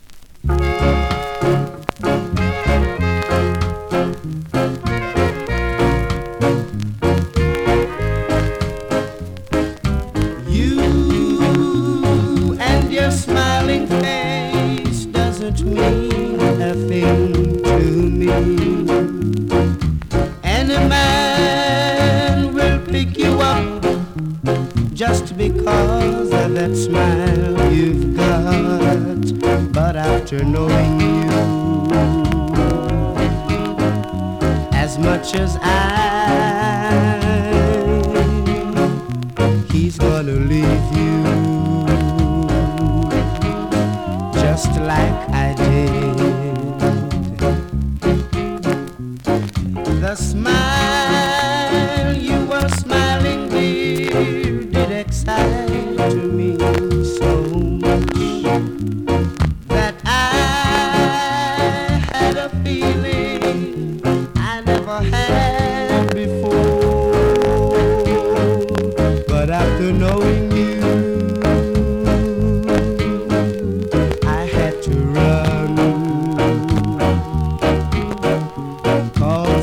※中盤でパチノイズ有